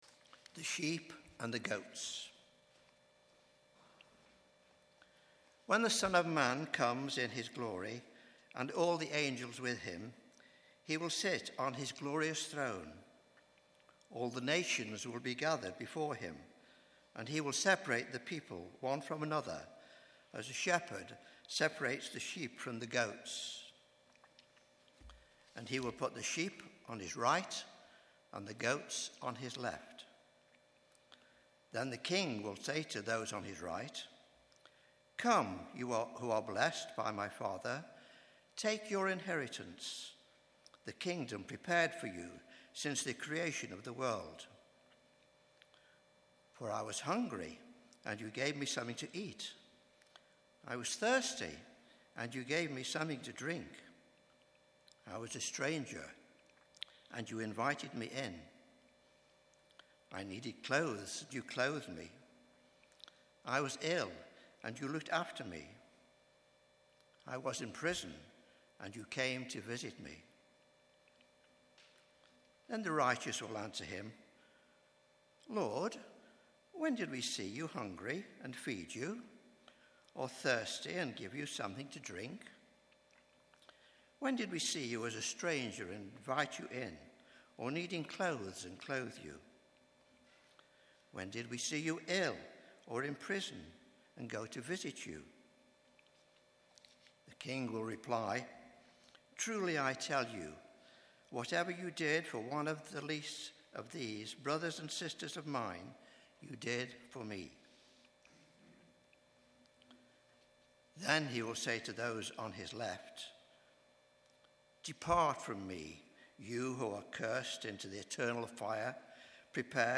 Bible Text: Matthew 25: 31-46 | Preacher